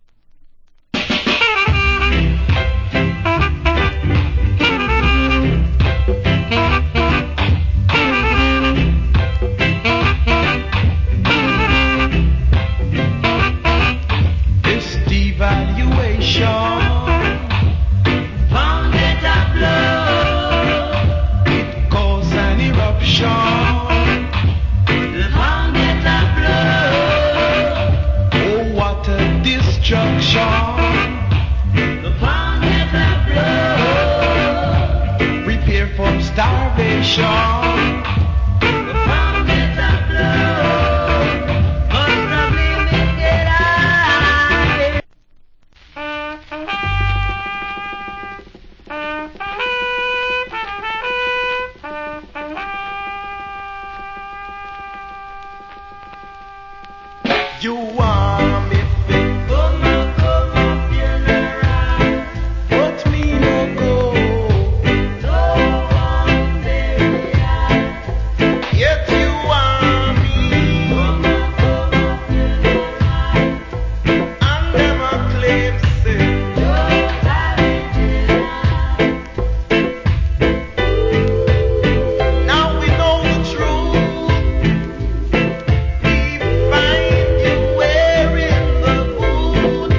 Great Rock Steady Vocal.